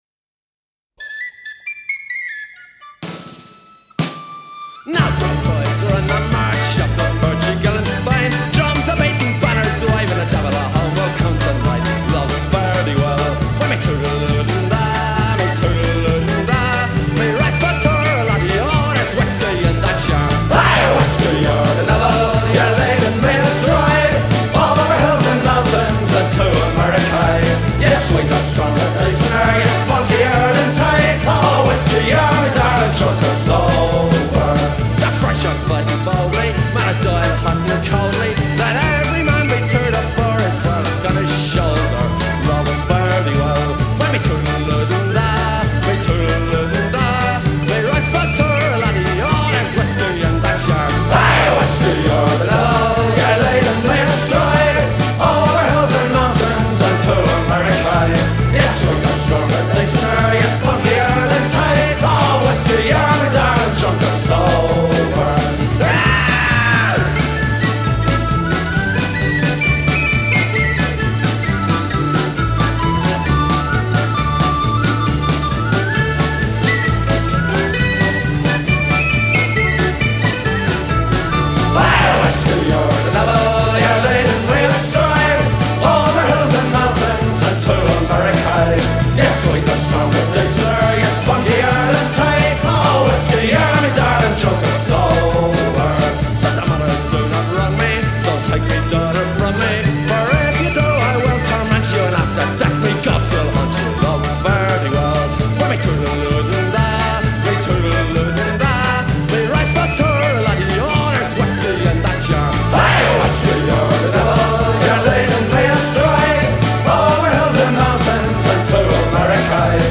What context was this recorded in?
BBC Live 1984-89